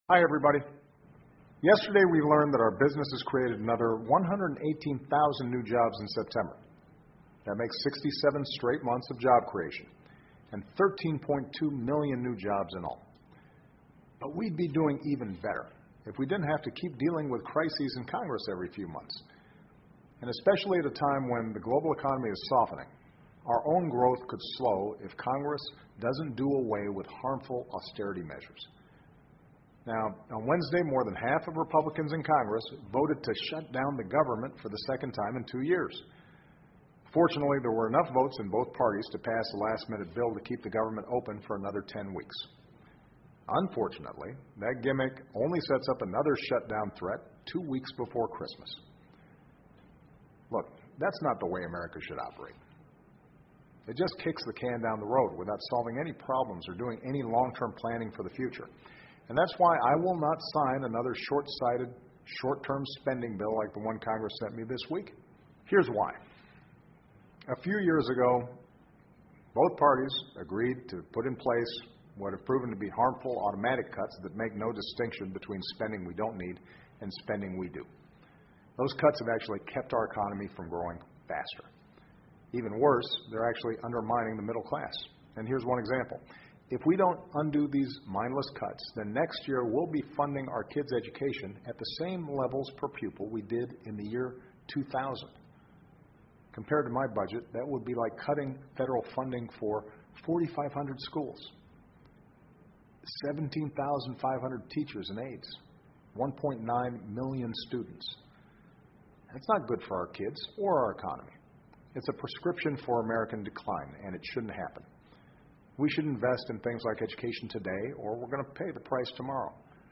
奥巴马每周电视讲话：总统呼吁国会尽快通过预算方案 听力文件下载—在线英语听力室